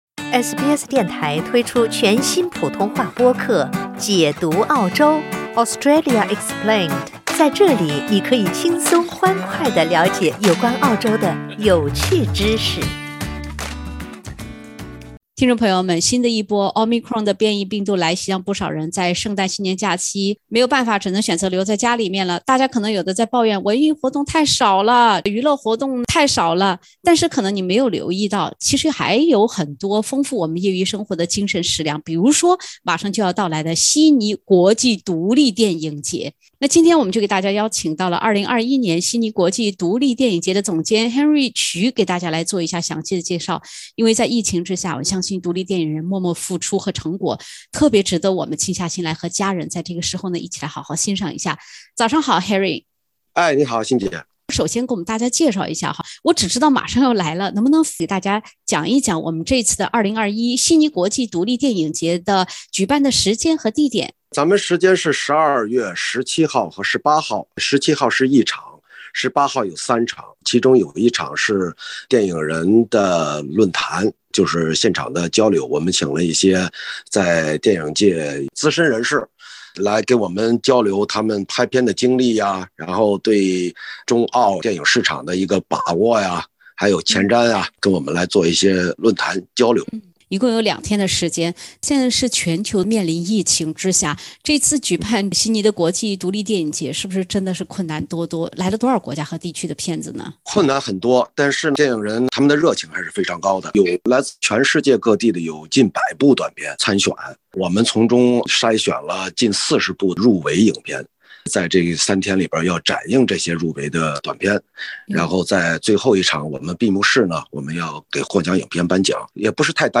悉尼国际独立电影节好片儿扎堆儿，伴你度过疫情“家里蹲”。（点击封面图片，收听完整采访）